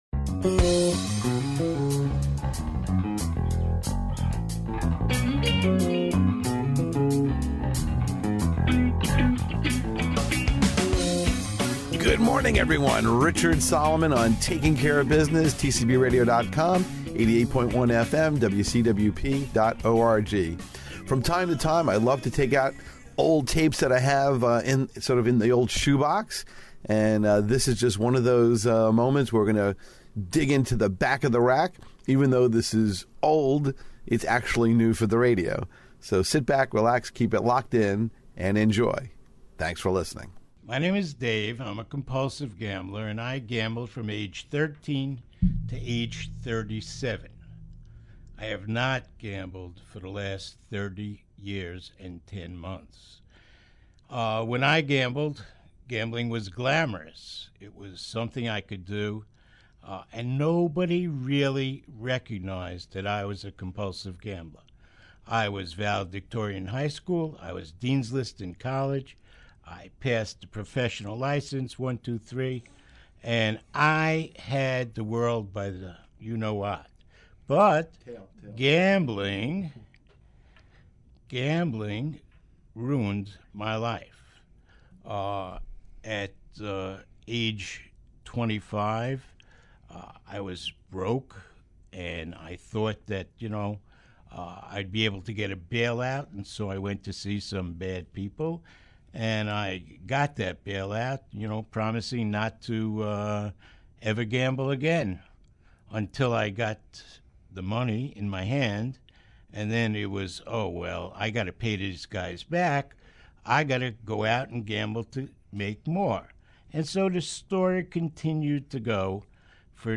Featured GA Speaker Audios